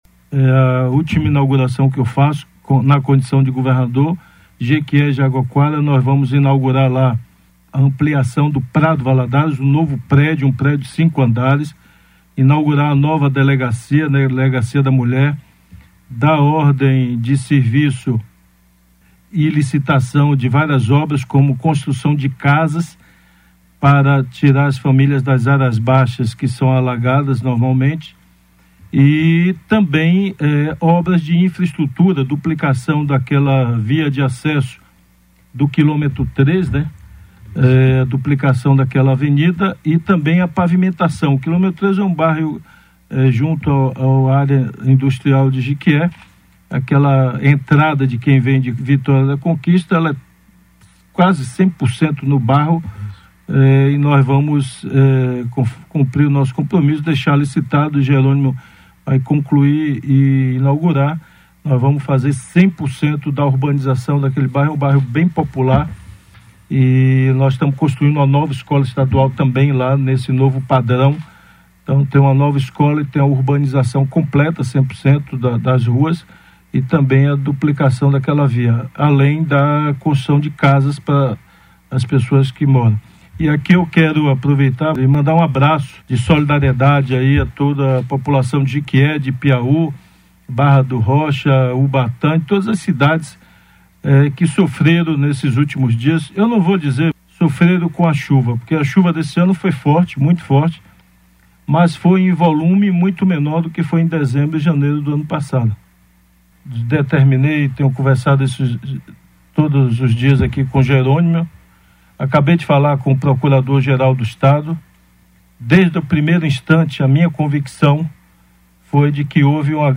SONORA DO GOVERNADOR RUI COSTA